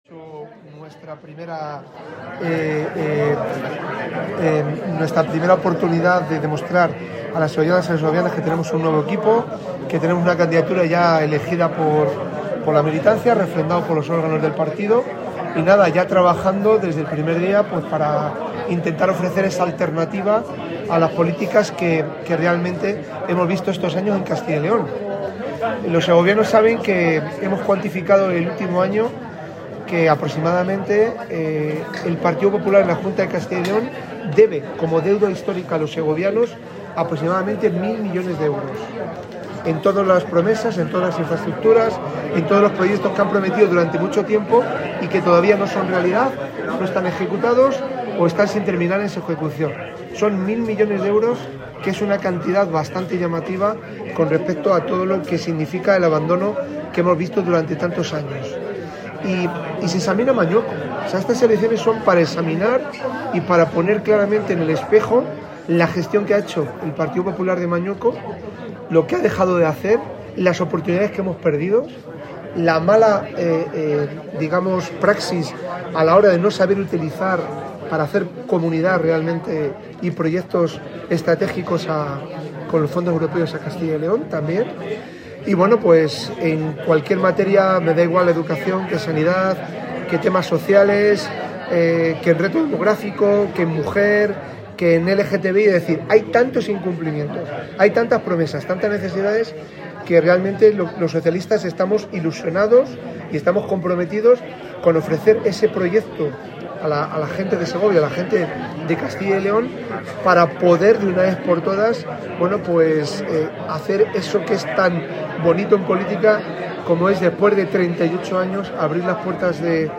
Segovia, 07 de enero de 2026: El PSOE de Segovia ha celebrado el tradicional vino de inicio de año, un encuentro que ha reunido a militantes, simpatizantes, alcaldes y alcaldesas, concejales y concejalas, cargos públicos socialistas y profesionales de los medios de comunicación.